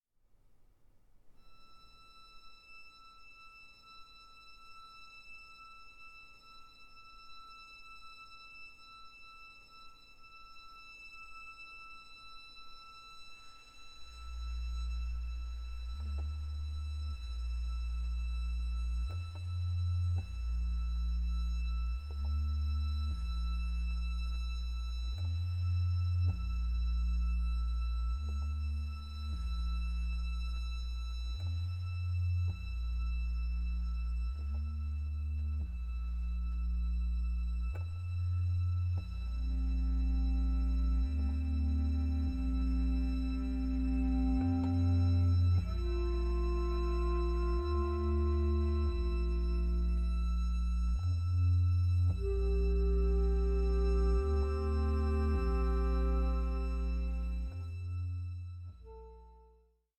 baroque orchestra